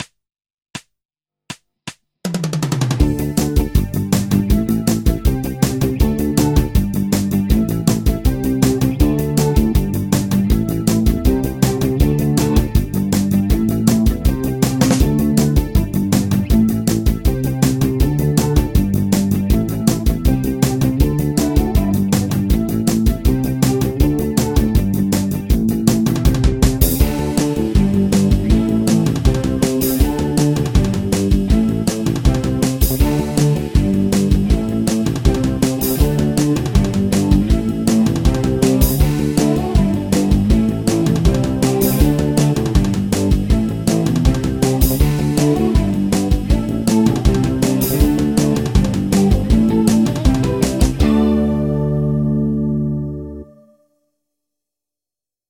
中国スケール ギタースケールハンドブック -島村楽器